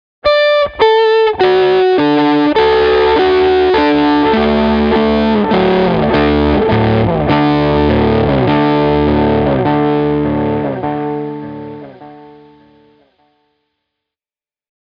The coolest bit about a bucket brigade delay is the sound of the delays, which are always rather lo-fi and slightly dirty, with the fidelity deteriorating further with each repeat.
The longer option lets you build Brian May -style walls of sound:
Akai Analog Delay – guitar wall
akai-analog-delay-layering.mp3